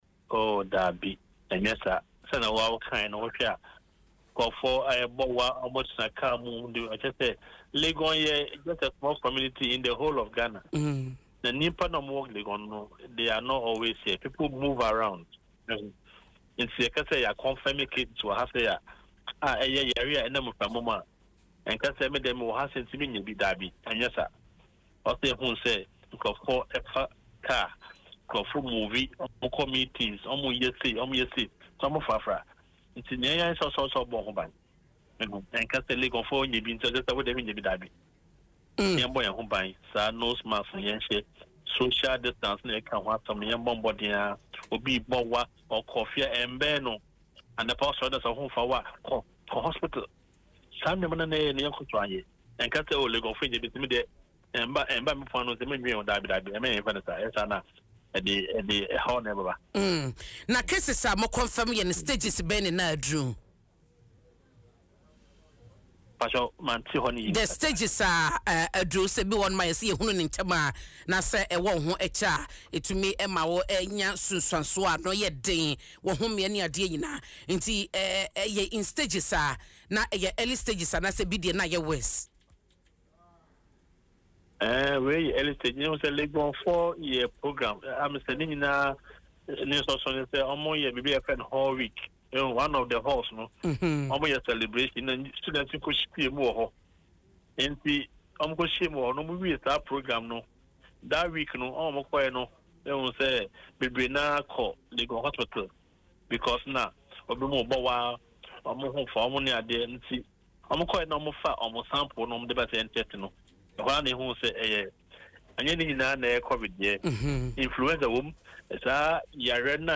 COVID-19 resurgence nationwide, not just Legon – Virologist warns [Audio]
Virologist-on-COVID-19-resurgence.mp3